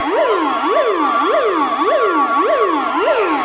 alarmSirenLoop.ogg